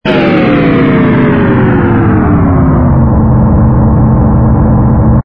engine_ku_freighter_land.wav